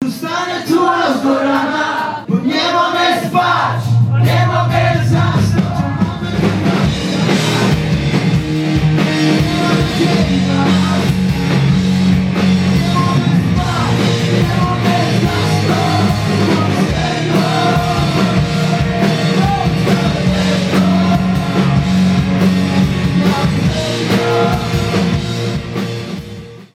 bas, gitara, perkusja